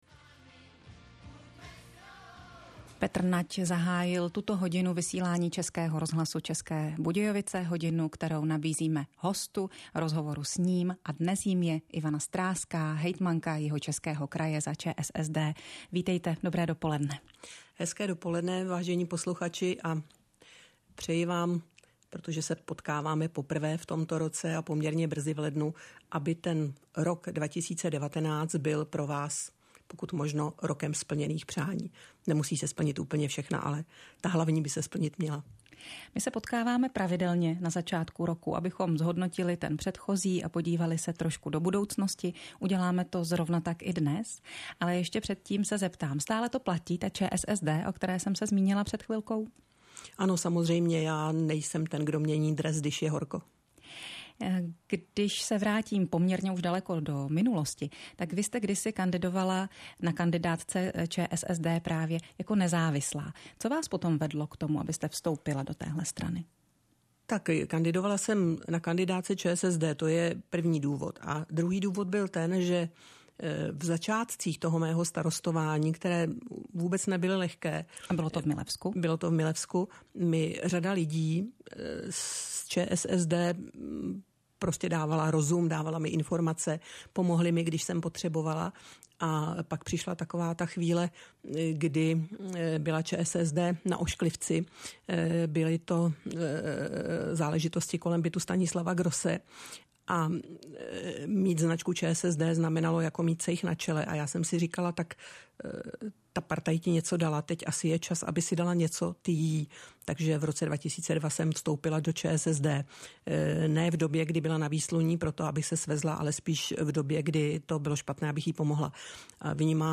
Celý rozhovor jihočeské hejtmanky Ivany Stráské pro Český rozhlas České Budějovice si můžete poslechnout zde: